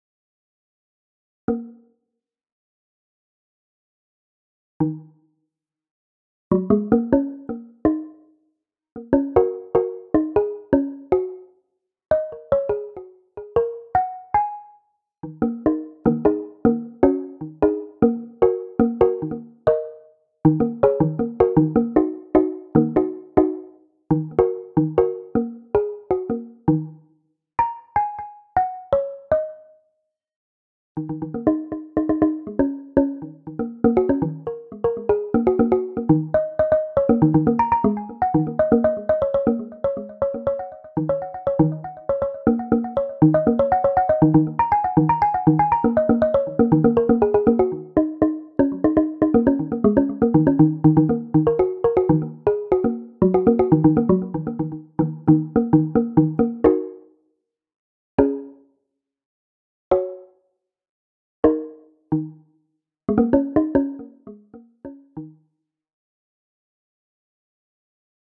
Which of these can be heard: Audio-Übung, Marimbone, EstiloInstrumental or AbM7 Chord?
Marimbone